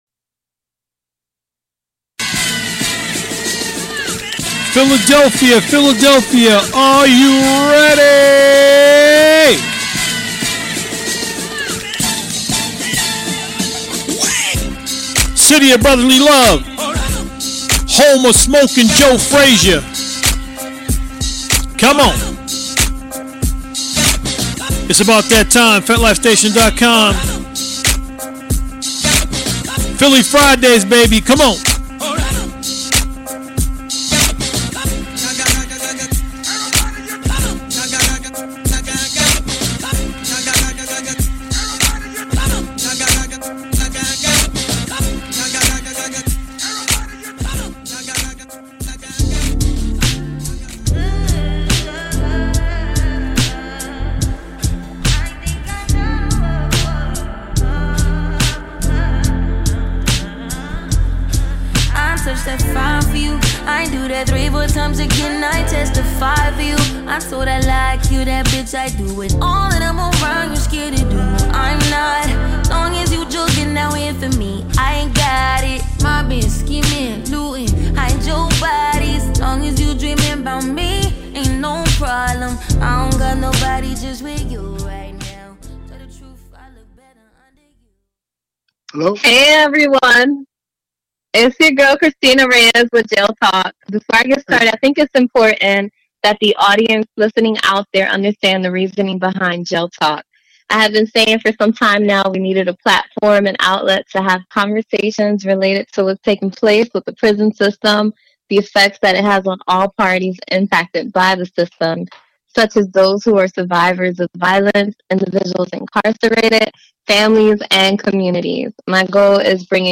interview people who have builded love relationships with people in prison.